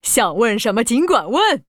文件 文件历史 文件用途 全域文件用途 Balena_tk_02.ogg （Ogg Vorbis声音文件，长度1.7秒，107 kbps，文件大小：22 KB） 源地址:游戏语音 文件历史 点击某个日期/时间查看对应时刻的文件。